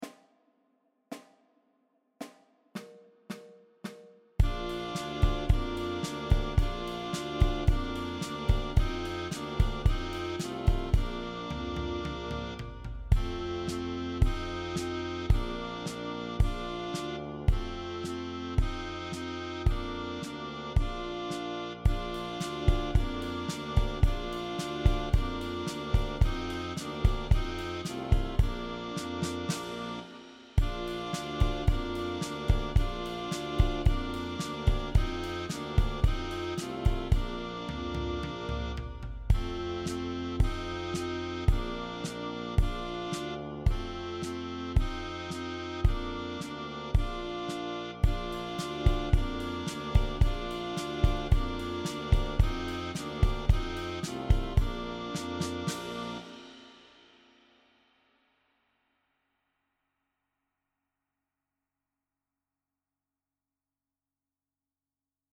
• 16 sehr leichte, dreistimmige Weihnachtslieder